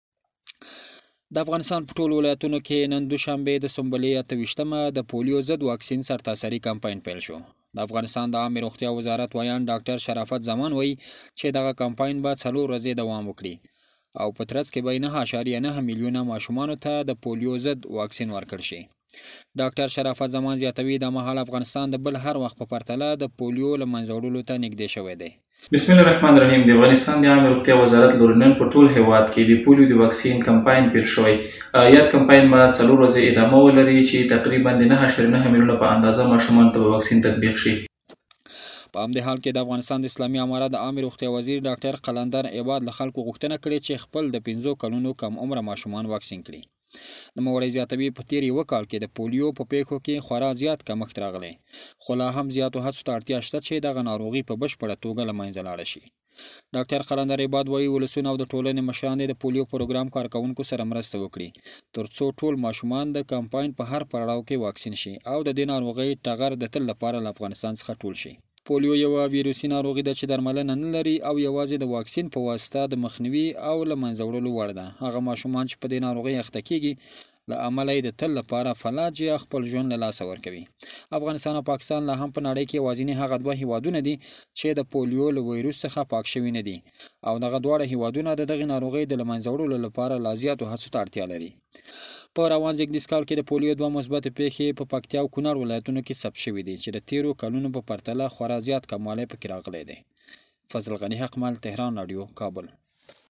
په دې اړه له کابله زمونږ دخبریال رالیږلی راپور په ګډه سره آورو .